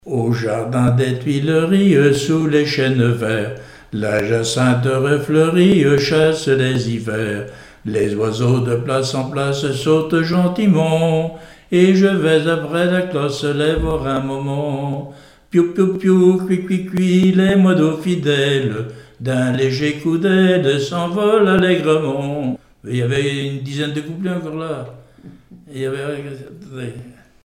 Genre brève
Témoignages et musiques
Pièce musicale inédite